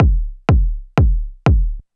First up is through my Hot Tubes. It doesn’t sound bad, sounds good, but it’s not the best I’ve heard either. would definitly use it in a track though.
It have been eq’ed and compressed when I made it, but had to eq it a bit more after the overdrive to get rid of too much low end and mids that got accentuated.
Now that I hear it uploadet the overdriven ones could have a bit more bottom maybe.